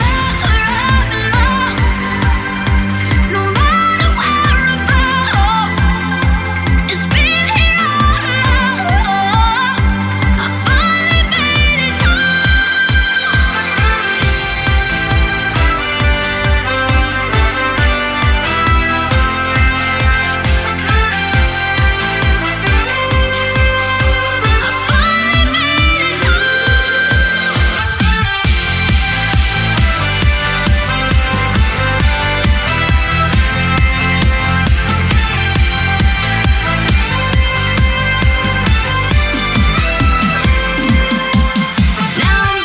Genere: club, edm, successi, remix